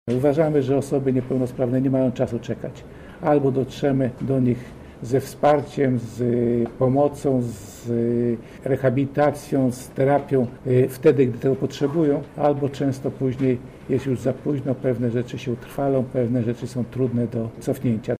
– mówi Krzysztof Michałkiewicz, Wiceminister rodziny, pracy i polityki społecznej.